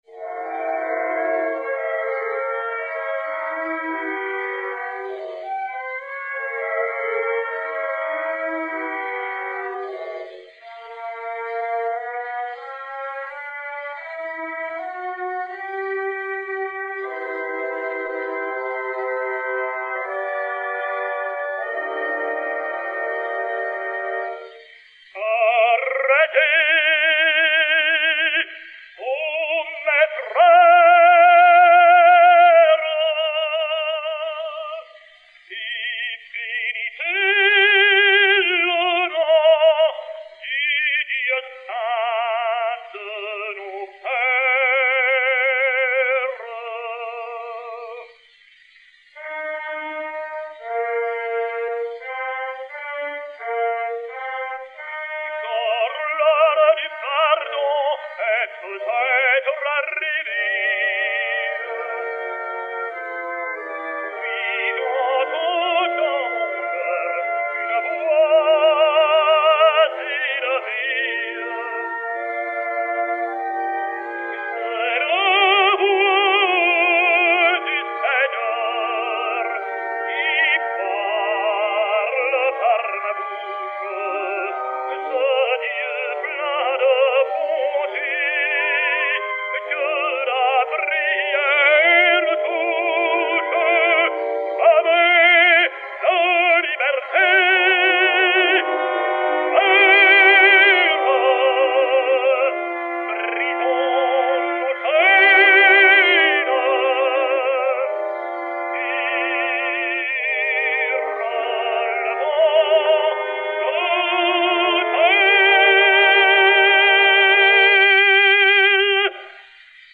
ténor français
Sa voix jeune et franche s'adapte au lyrisme des hymnes et des invocations martiales aussi bien qu'aux effusions, aux plaintes ou aux caresses amoureuses.
Charles Dalmorès (Samson) et Orchestre Victor
B11153-2, enr. à Camden, New Jersey, le 30 octobre 1911